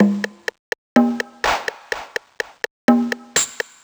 cch_jack_percussion_loop_cavern_125.wav